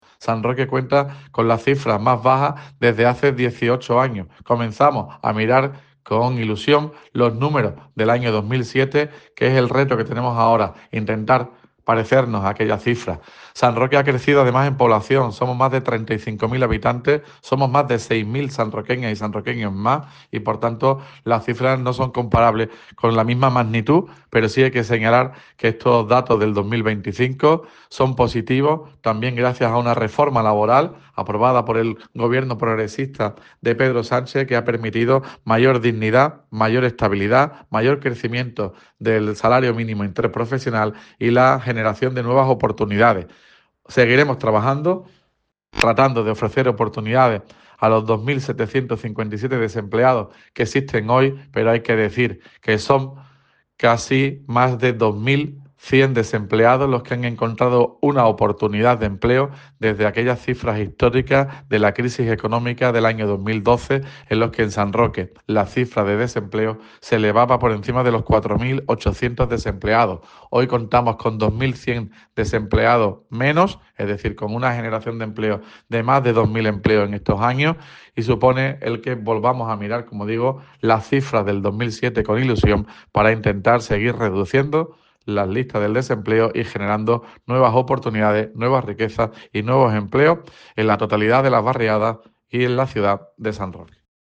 ALCALDE BAJADA PARO MAYO.mp3